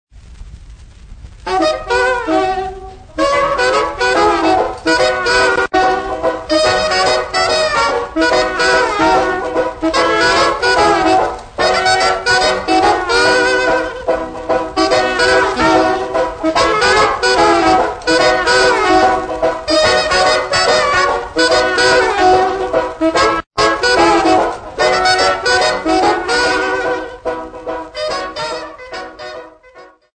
Folk Music
Field recordings
Africa Zimbabwe Bulawayo f-rh
sound recording-musical
Indigenous music